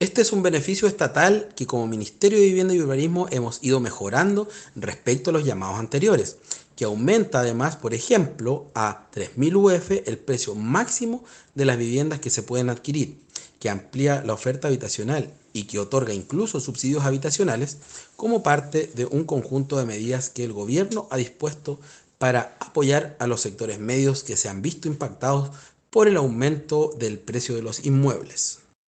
El secretario regional ministerial del MINVU en Los Lagos, Fabián Nail, indicó que el Subsidio para Sectores Medios es un beneficio estatal que ha sido mejorado respecto a llamados anteriores, que aumenta por ejemplo a 3 mil UF el precio máximo de las viviendas que se pueden adquirir.